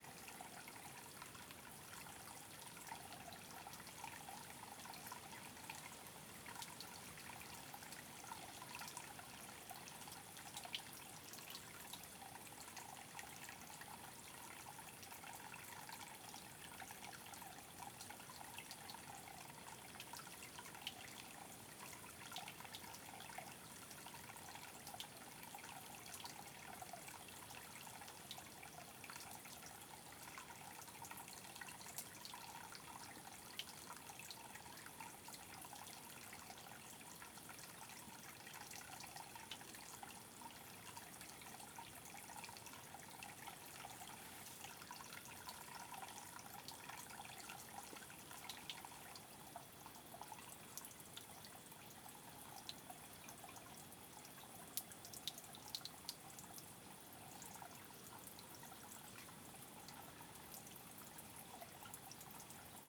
Varias nascentes e pequenas quedas dagua juntas na Chapada dos Veadeiros
Cachoeira pequena
Nascente de água Alto Paraíso de Goiás Stereo
CSC-01-022-GV - Varias nascentes e pequenas quedas dagua juntas na Chapada dos Veadeiros.wav